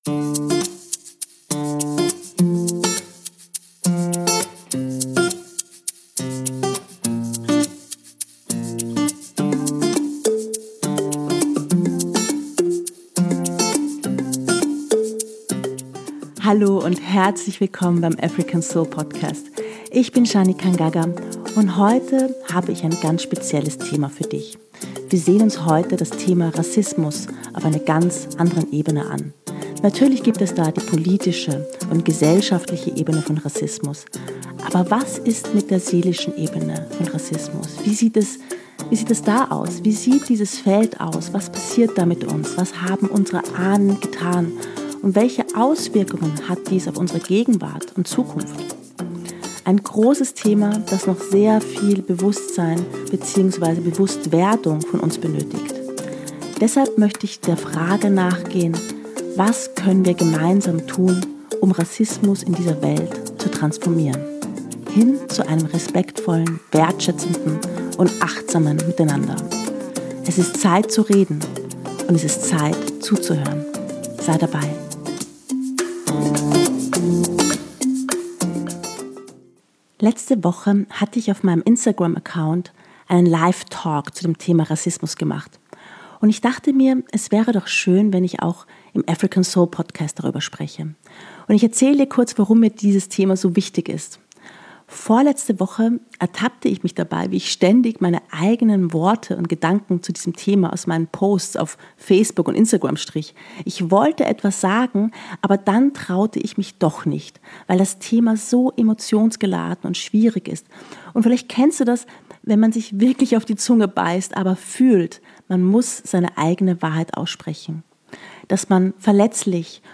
Abend Meditation - Lass los und komme zur Ruhe
Ich möchte dir heute deshalb eine schöne Abendmeditation mitgeben, die deinen Geist beruhigt und dich entspannen soll. Die Meditation wird dir helfen, mit Klarheit, Entspannung und Ruhe einzuschlafen.